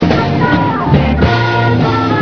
il Flauto